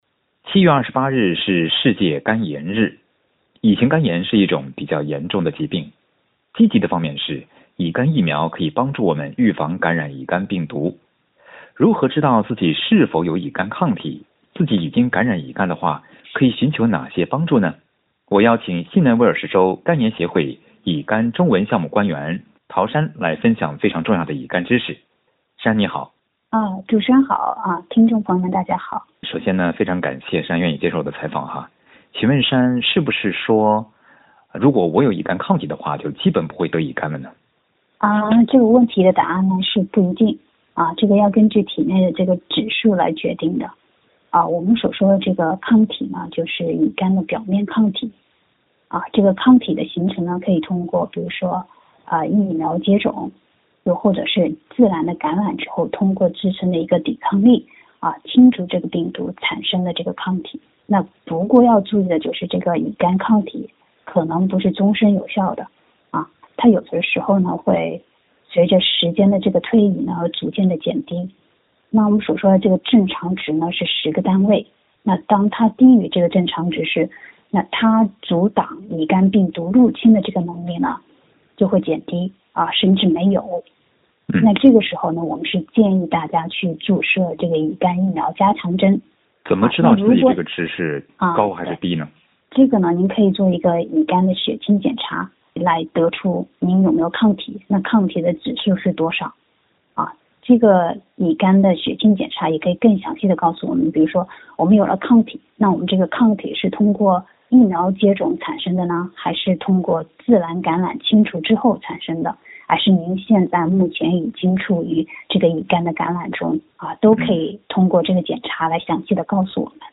在接受SBS普通话采访时